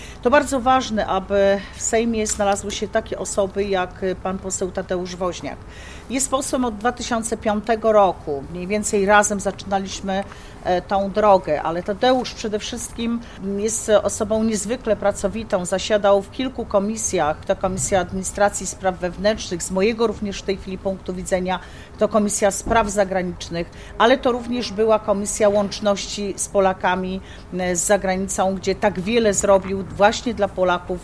Beata Kempa popiera kandydaturę Tadeusza Woźniaka Podczas spotkania w Sieradzu Beata Kempa mówiła o wieloletniej współpracy z posłem i jego sejmowej działalności.